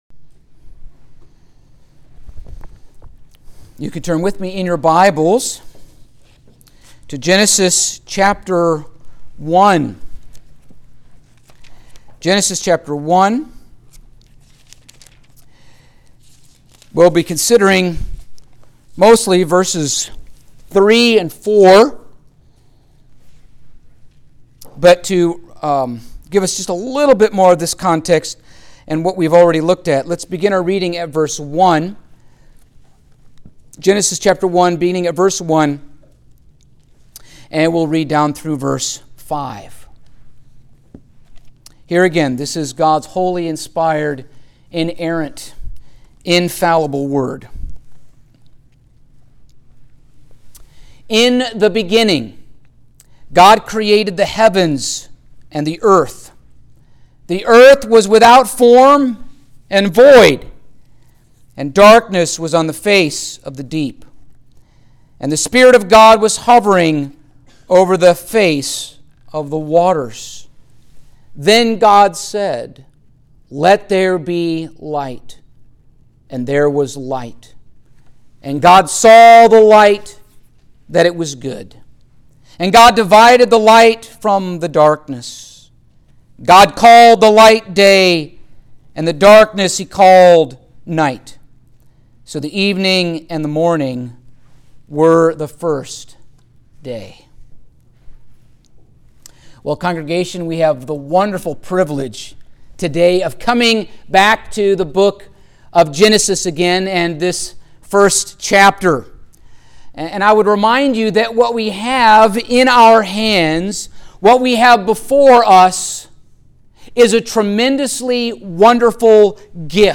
Genesis Passage: Genesis 1:3-4 Service Type: Sunday Morning Topics